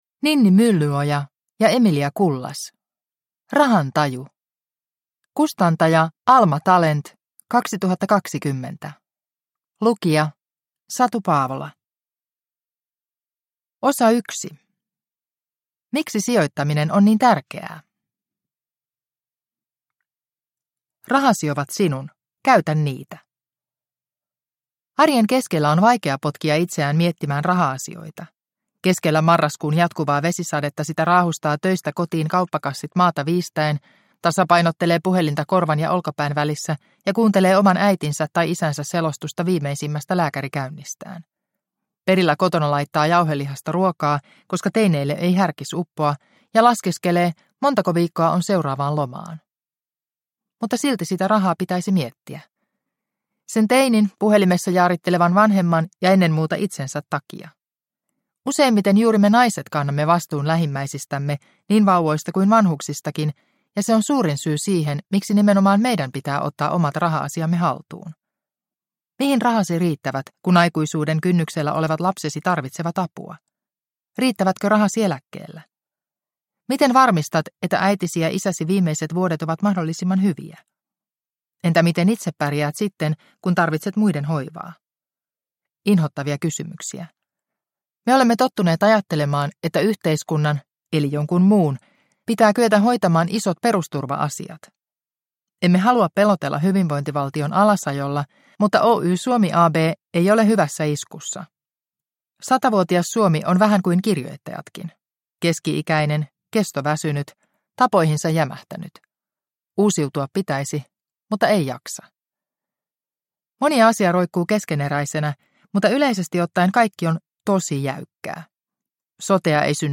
Rahan taju – Ljudbok – Laddas ner